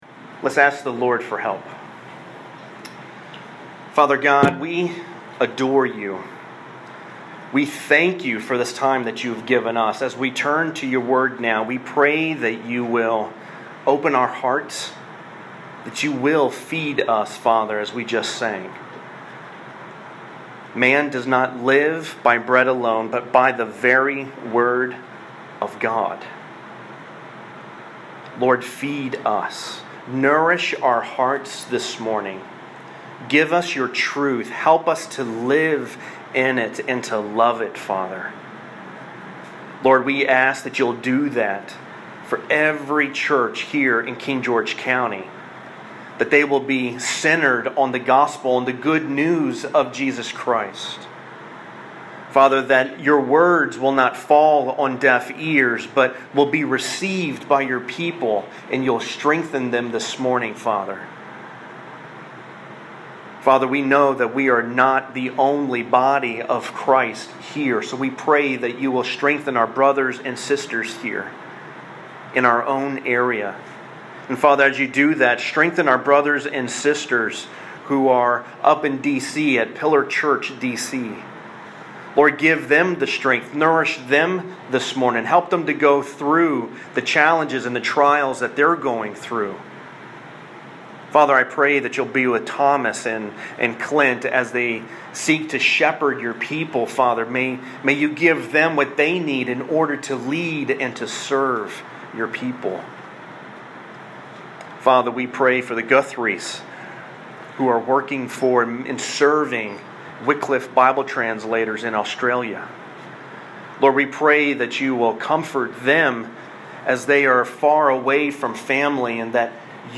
Ruth Conclusion — The Simplicity and Complexity of Ruth - Redeemer Church KG